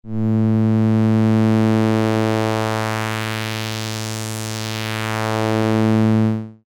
Audio demo (for comparison) :
MOS-6581 Filter Sweep BP
MOS6581_BP_Filter_sweep.mp3